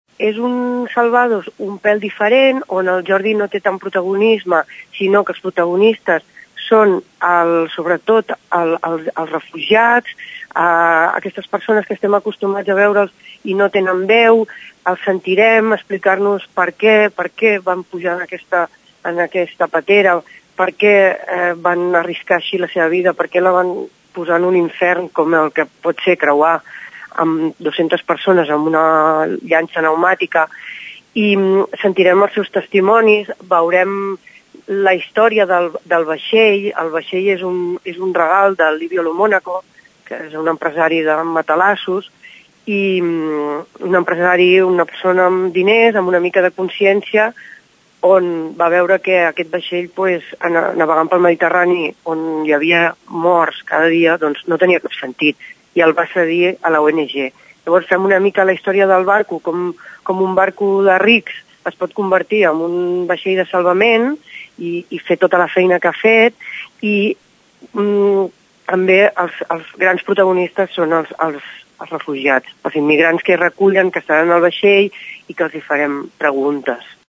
El documental, realitzat al costat de l’ONG Proactiva Open Arms, narra el drama dels refugiats que intenten travessar el Mediterrani a la recerca d’una vida millor a Europa. Ho explica en declaracions a Ràdio Tordera